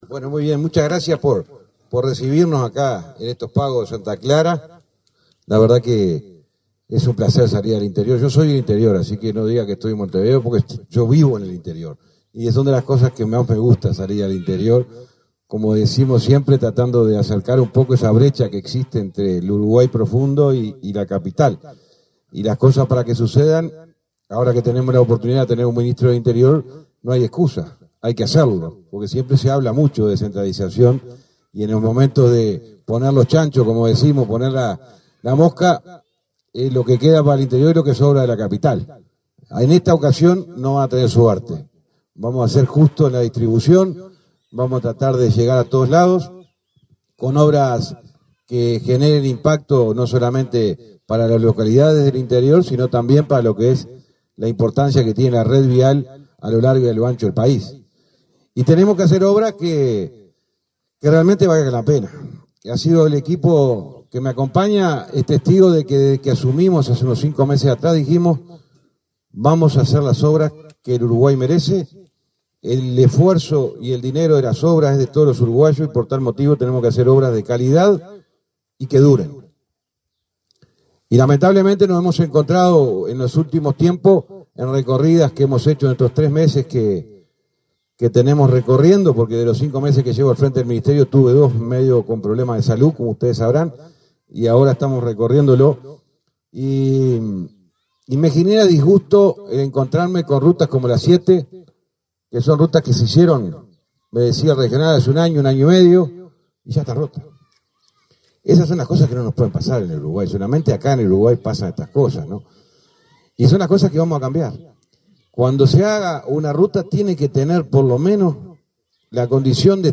Conferencia de prensa por la firma de convenio entre el MTOP y la Intendencia de Treinta y Tres
En Treinta y Tres, hablaron el ministro José Luis Falero y el intendente Mario Silvera.